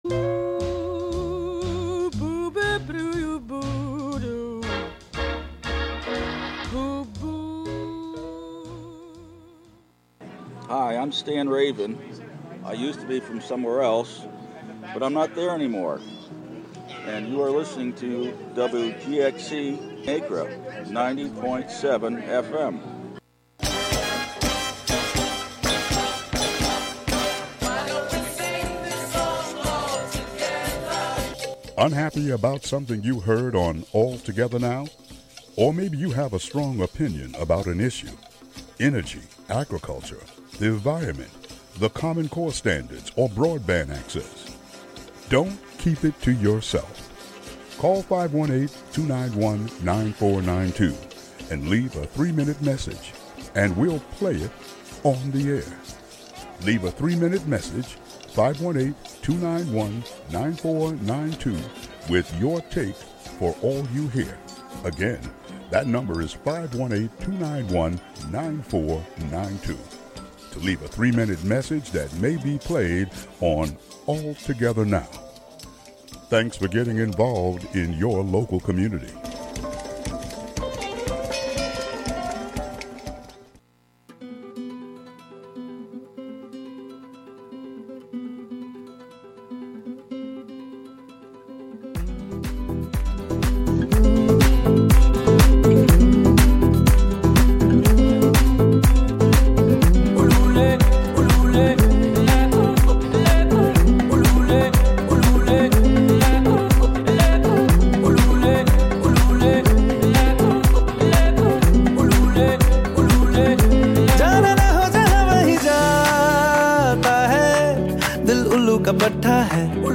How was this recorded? Broadcast live from the Hudson studio.